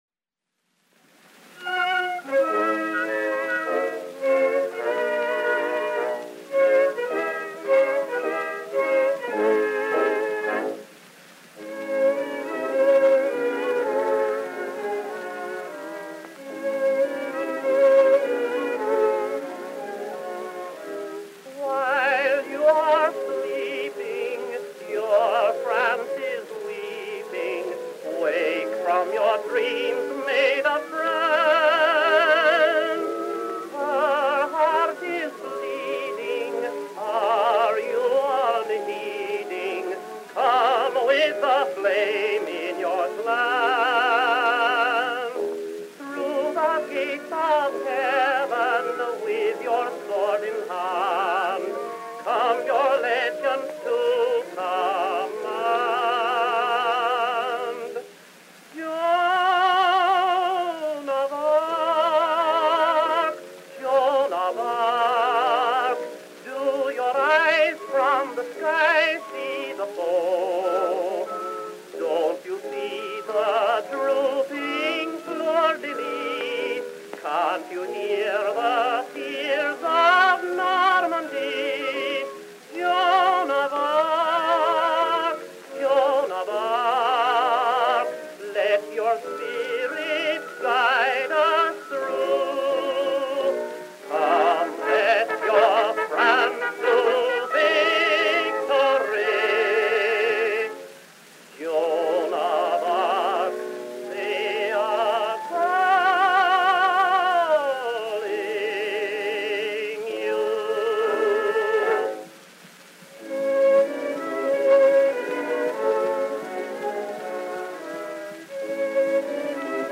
Tenor solo with orchestra accompaniment.